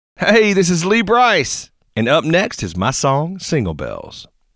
Liners